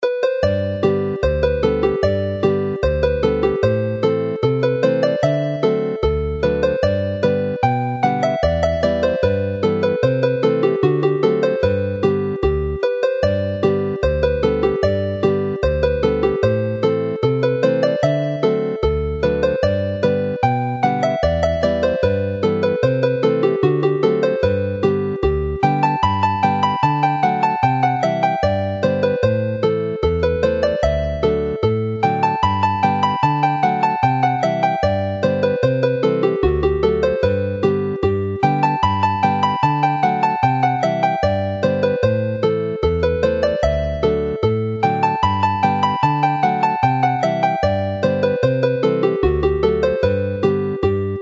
Alawon Cymreig - Set yr Aradr - Welsh folk tunes to play